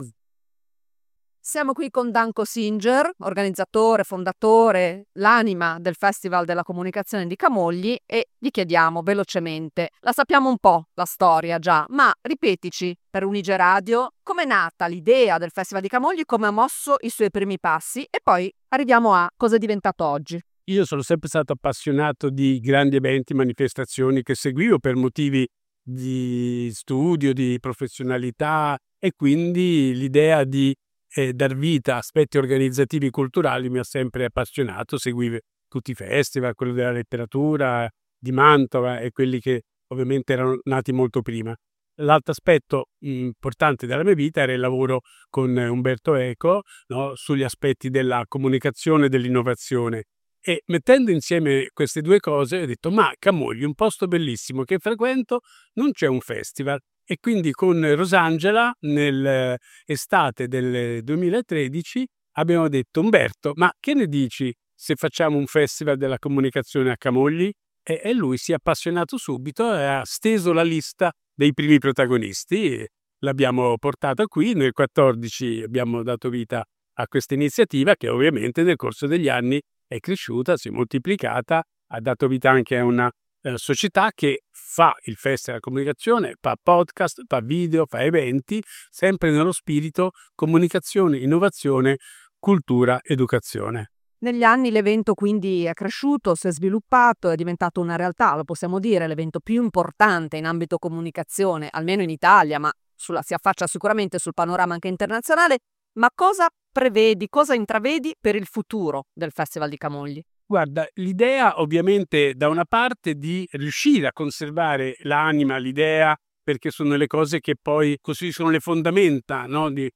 UniGE al Festival della Comunicazione 2025